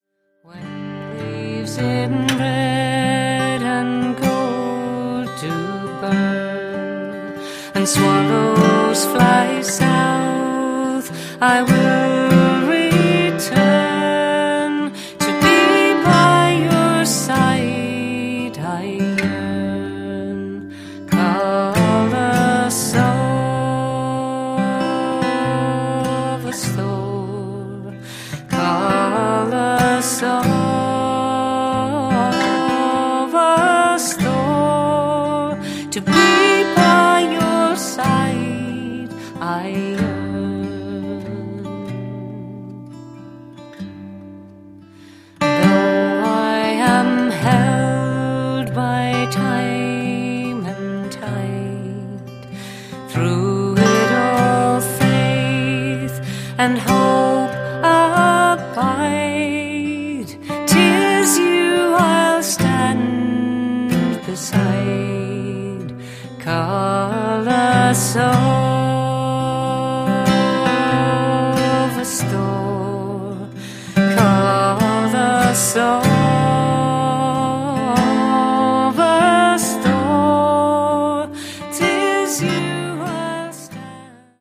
the sparse gentleness of his playing